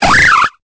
Cri de Sapereau dans Pokémon Épée et Bouclier.